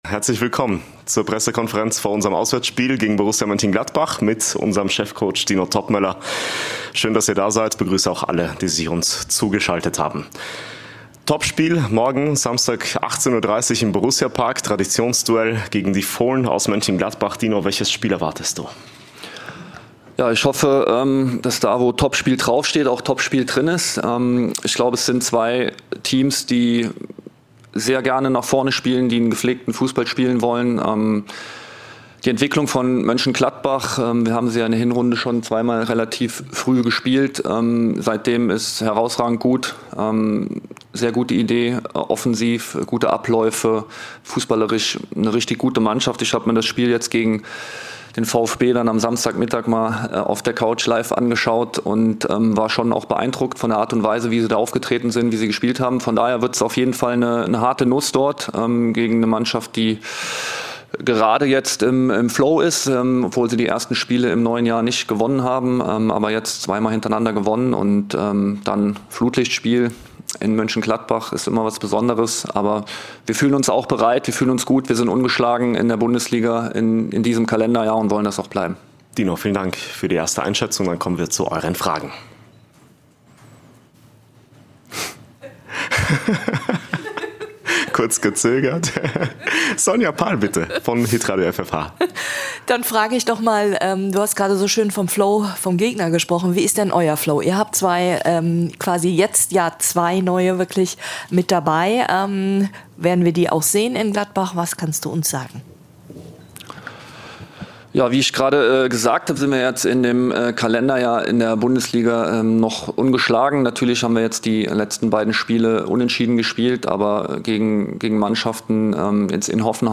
Die Pressekonferenz mit Cheftrainer Dino Toppmöller vor dem Bundesliga-Auswärtsspiel bei Borussia Mönchengladbach.